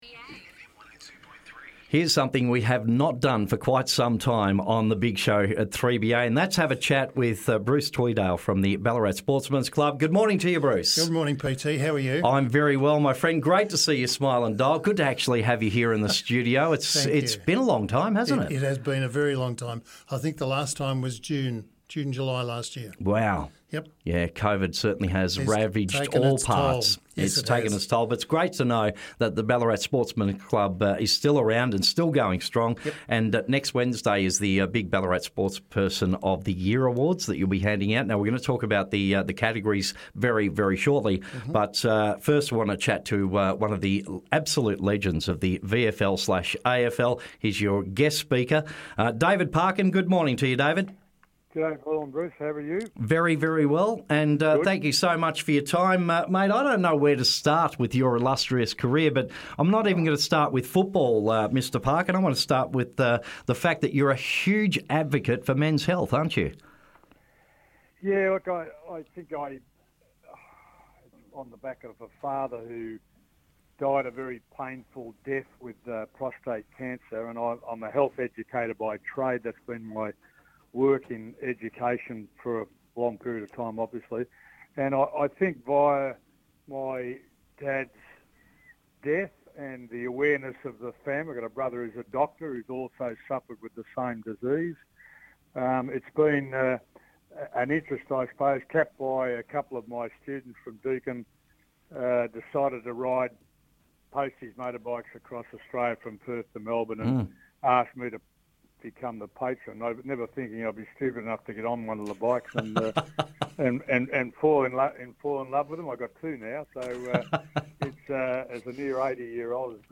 A Chat with VFL/AFL Legend David Parkin